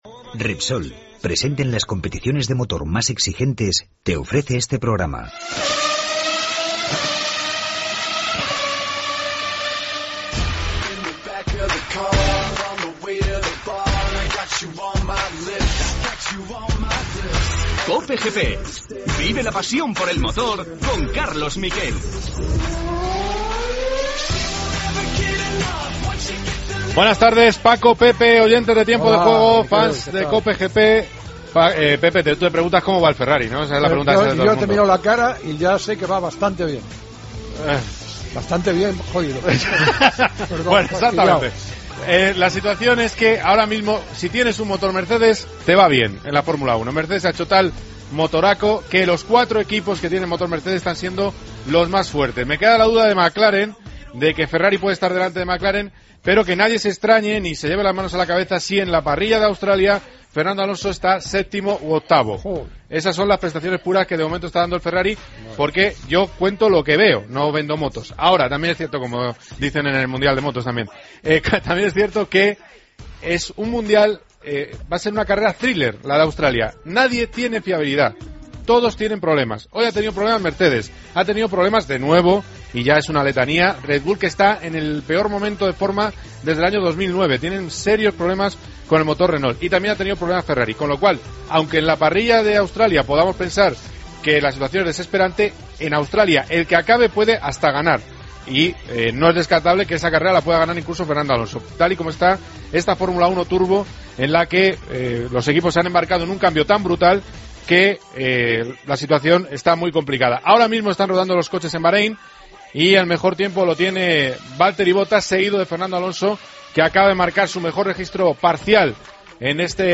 Entrevista a Álvaro Bautista.